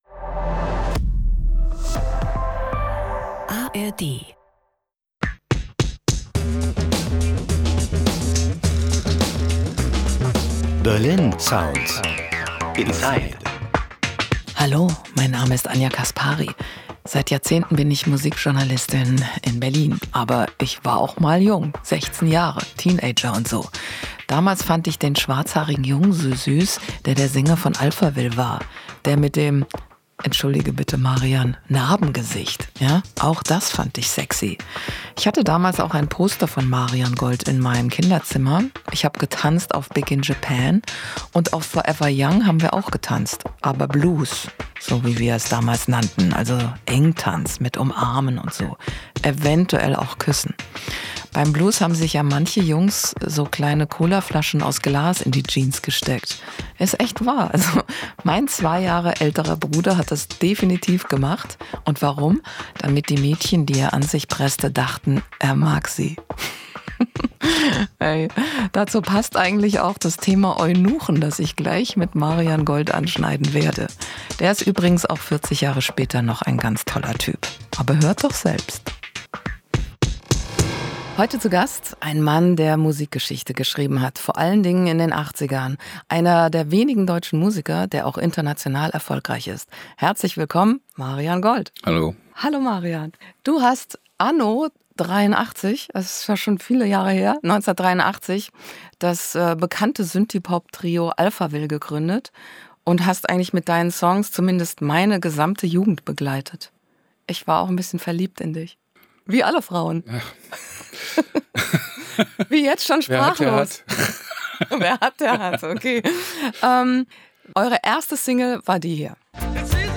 "Ich war Punk in Berlin" MARIAN GOLD über die 80er mit Alphaville ~ Berlin Sounds Inside – Der Musiktalk